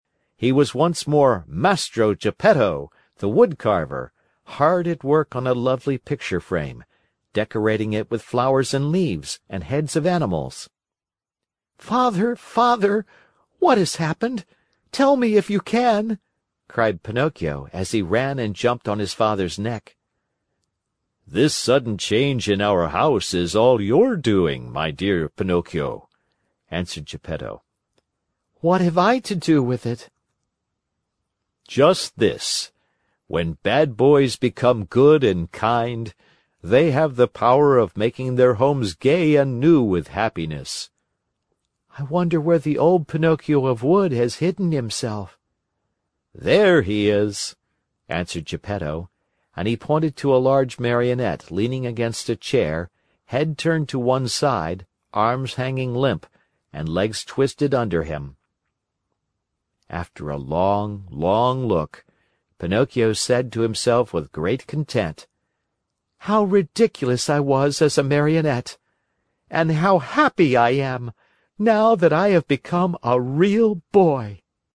在线英语听力室木偶奇遇记 第167期:匹诺曹梦想成真(13)的听力文件下载,《木偶奇遇记》是双语童话故事的有声读物，包含中英字幕以及英语听力MP3,是听故事学英语的极好素材。